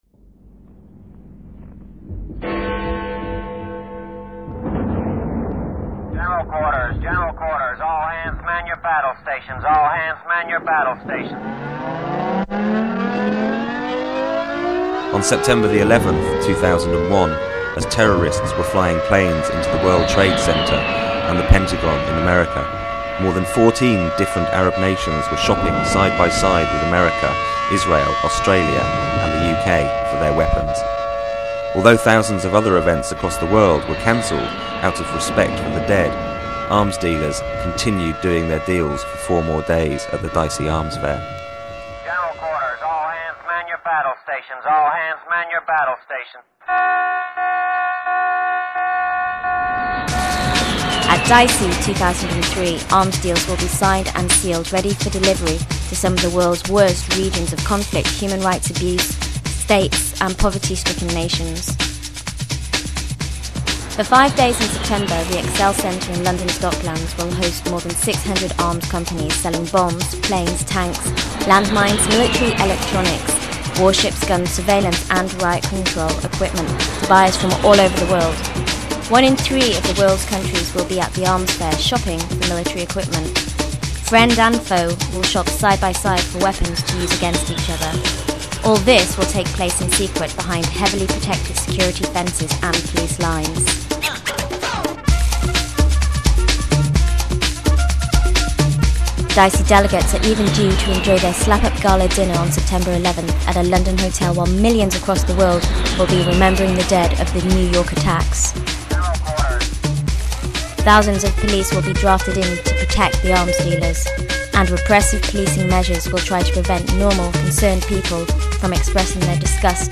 Download: one bell - mp3 1022K